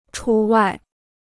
除外 (chú wài) Dictionnaire chinois gratuit